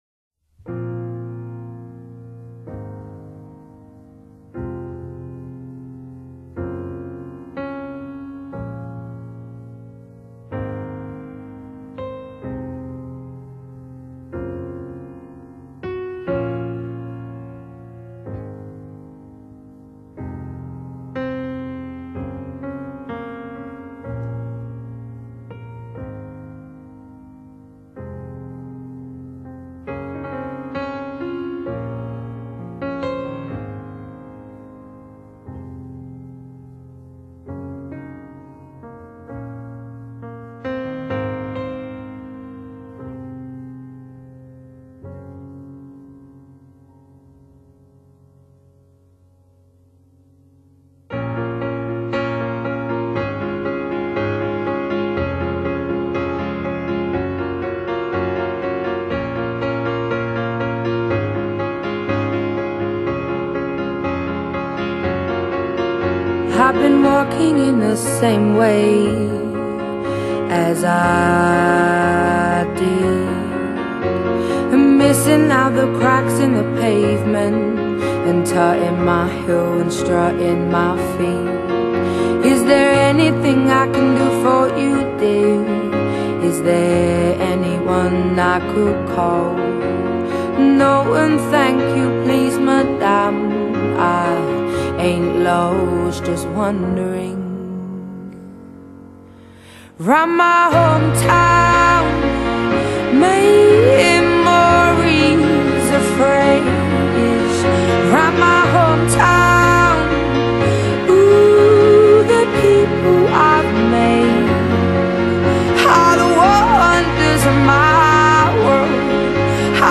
2008 Genre: Soul | Indie Pop | R&B Quality